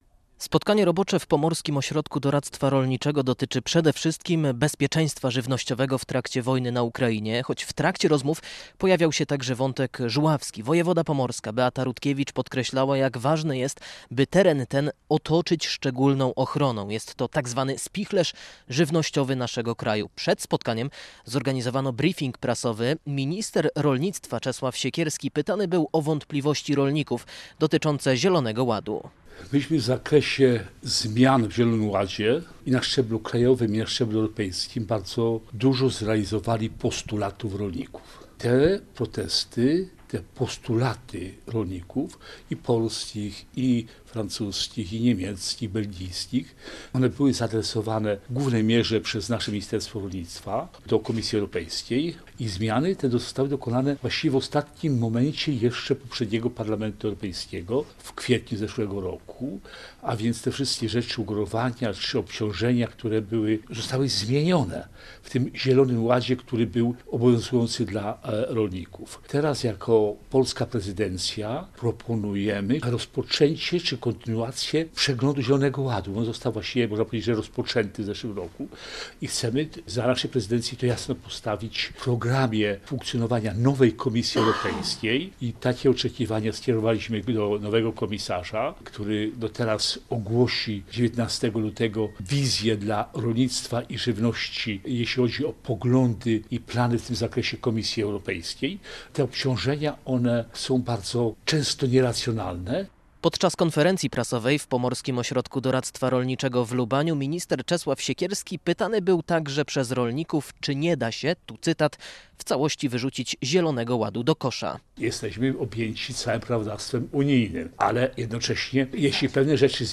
To niektóre tematy, które pojawiły się podczas roboczego spotkania w Pomorskim Ośrodku Doradztwa Rolniczego w Lubaniu z udziałem ministra